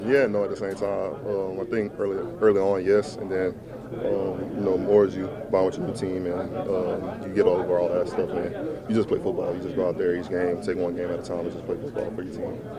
Linebacker Patrick Queen met with reporters following practice to discuss the rivalry between the Steelers and Ravens, as he’ll now experience it from the other sideline. He said he’s had this game circled for a while.